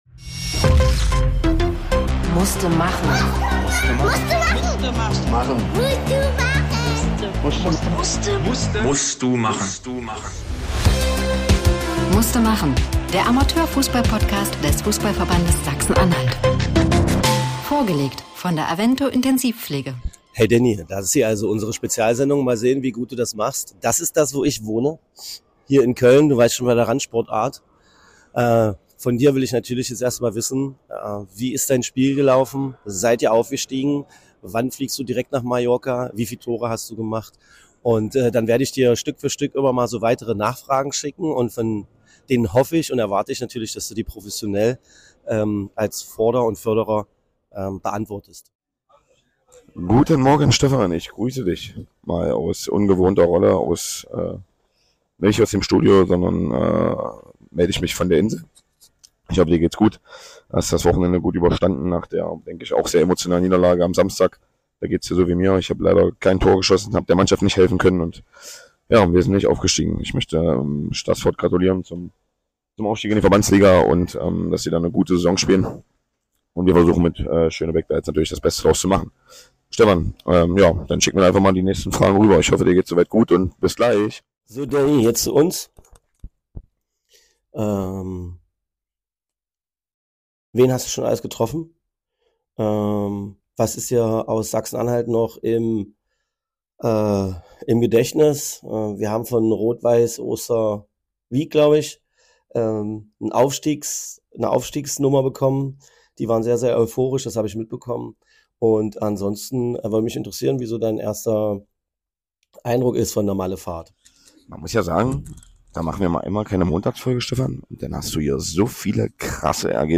Aufstiege, kratzige Stimmen und ne Ansage, diese Folge ist für Fans